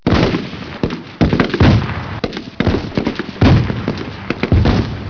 Totally Free War Sound Effects MP3 Downloads
Infantryattacks.mp3